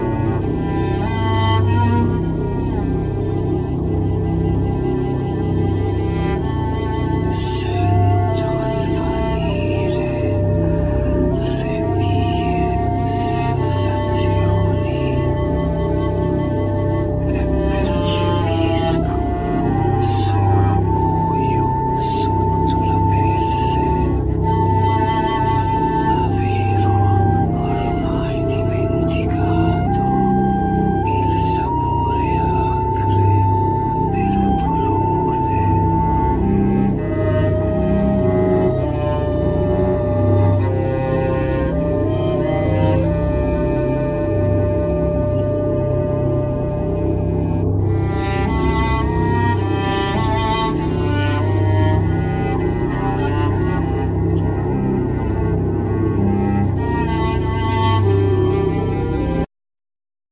Voice,Keyboards,Dong ruan,Samplers,Percussions
Classic guitar
Bassoon
Cello
Harp
Trumpet,Piccolo trumpet
Flute
Oboe
Timpanos,Snare,Drum,Cymbals,Gong
Chinese voice
Clarinet